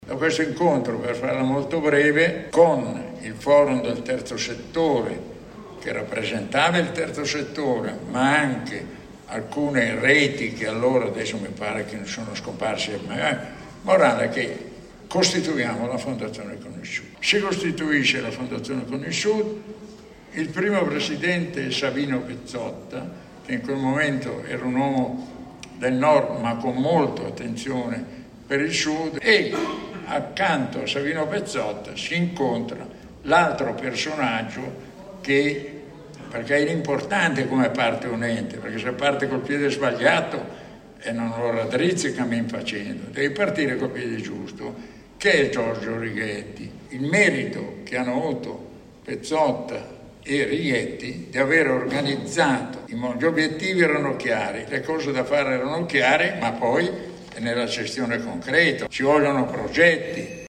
Partendo da questa premessa, Fondazione con il Sud ha organizzato al Rione Parco Verde di Caivano, a Napoli, l’incontro “Un futuro già visto”.
Questa la testimonianza di Guzzetti.